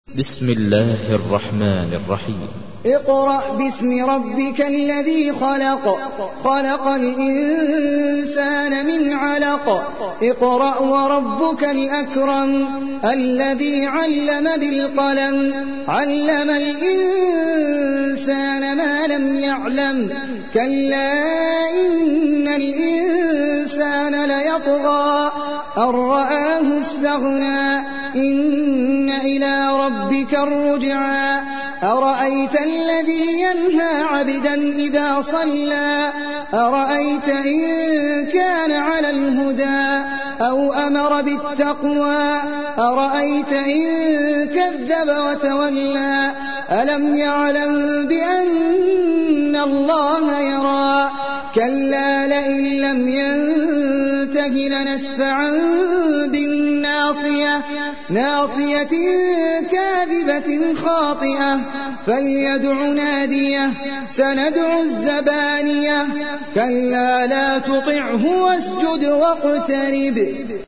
تحميل : 96. سورة العلق / القارئ احمد العجمي / القرآن الكريم / موقع يا حسين